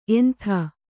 ♪ entha